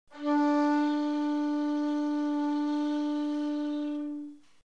shakuhachi
Shakuhachi notes
RoExtremeofVibrato.mp3